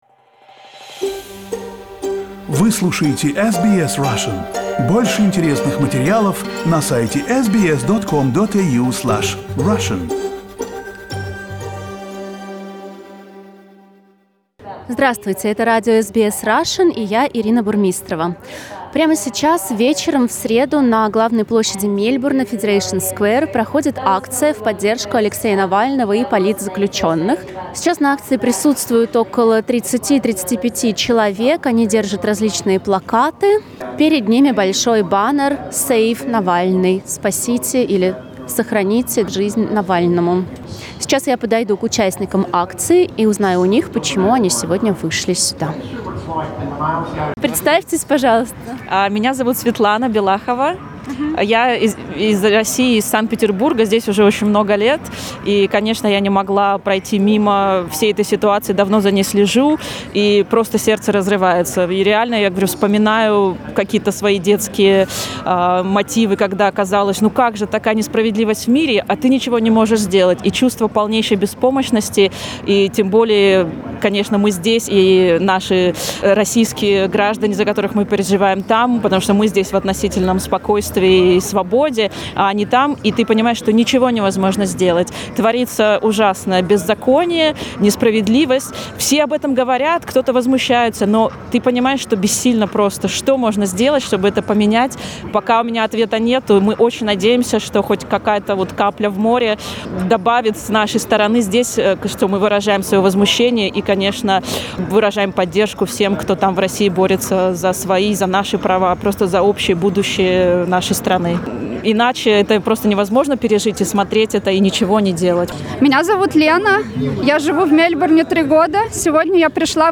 On Wednesday, April 21, rallies and single pickets were held in various cities of Australia and New Zealand in support of the Russian opposition politician Alexei Navalny, who is now in prison. We asked the participants of the events why it was important for them to come out and express their position.